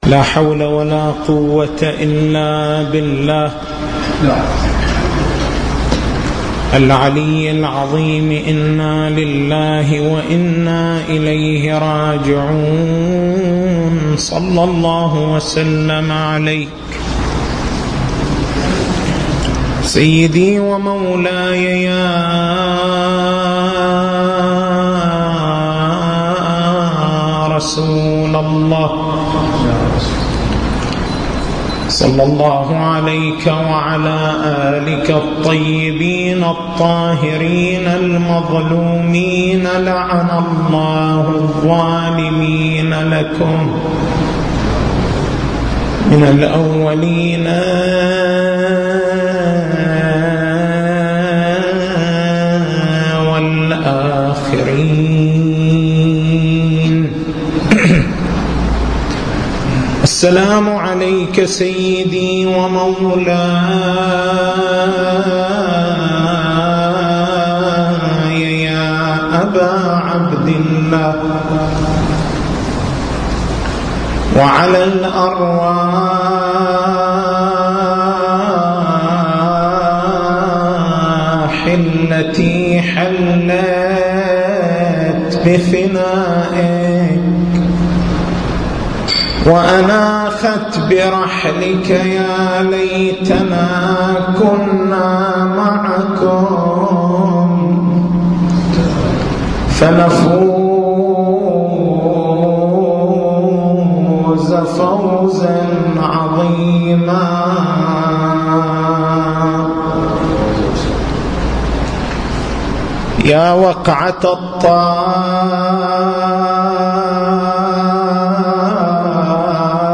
تاريخ المحاضرة: 02/09/1434 محور البحث: تحليل ما ورد عن الإمام الصادق عليه السلام: ((الشقيّ حقّ الشقيّ من خرج عنه شهر رمضان ولم تُغْفَر له ذنوبُه)).